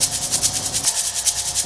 Shaker FX 01.wav